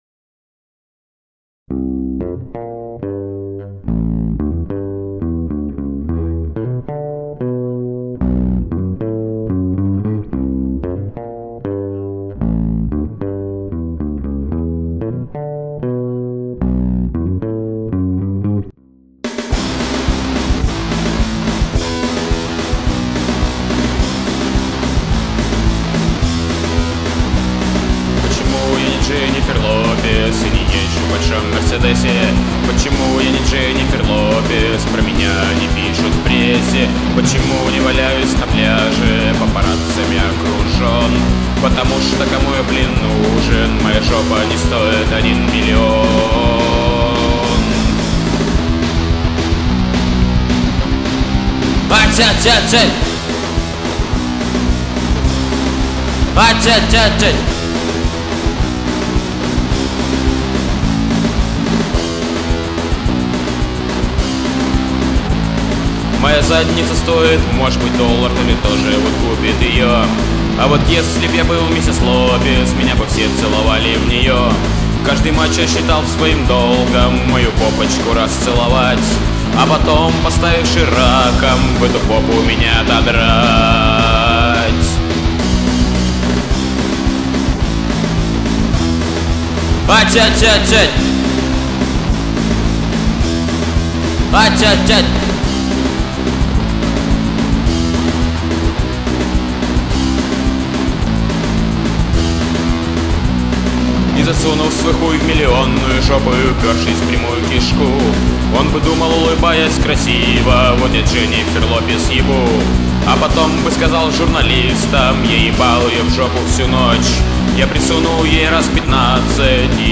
Демо